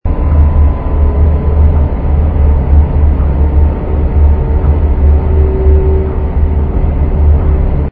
rumble_miner.wav